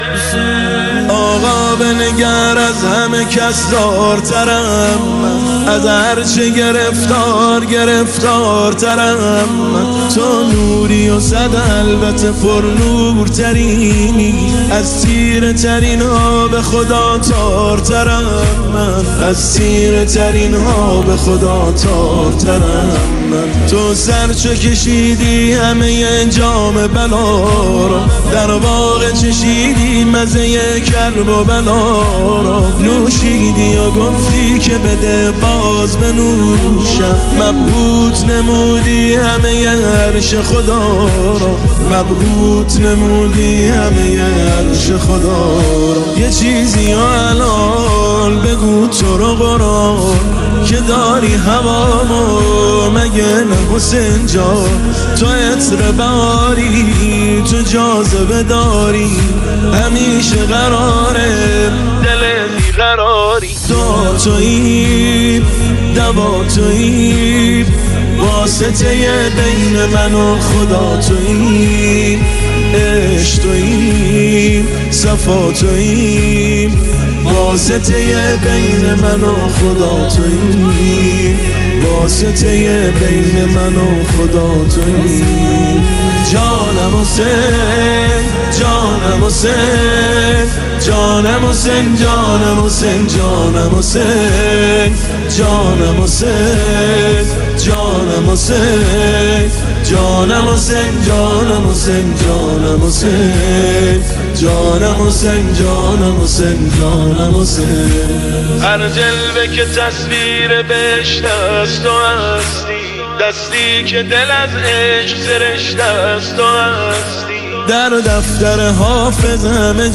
تنظیم استودیویی - سال 1404